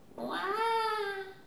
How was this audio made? ajout des sons enregistrés à l'afk ... Les sons ont été découpés en morceaux exploitables. 2017-04-10 17:58:57 +02:00 256 KiB Raw History